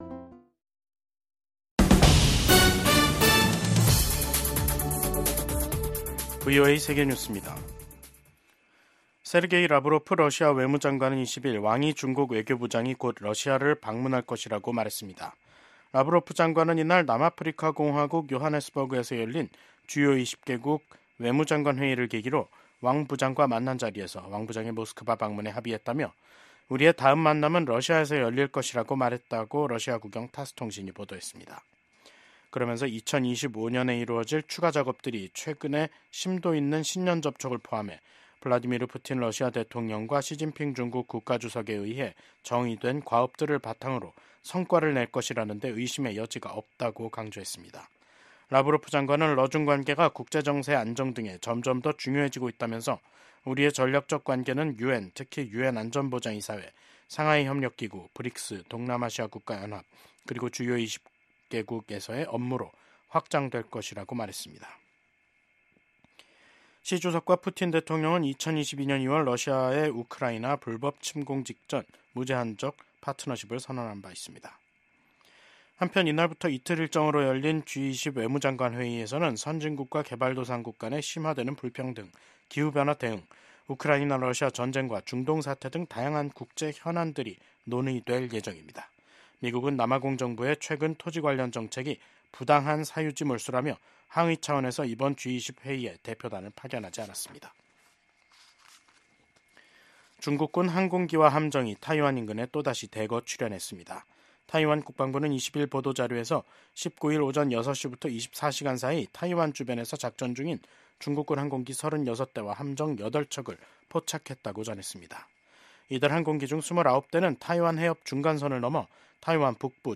VOA 한국어 간판 뉴스 프로그램 '뉴스 투데이', 2025년 2월 20일 3부 방송입니다. 미국 정부는 중국이 타이완 해협의 평화와 안정을 해치고 있다는 점을 지적하며 일방적 현상 변경에 반대한다는 입장을 확인했습니다. 미국의 ‘핵무기 3축’은 미국 본토에 대한 북한의 대륙간탄도미사일 공격을 효과적으로 억지할 수 있다고 미국 공군 소장이 말했습니다.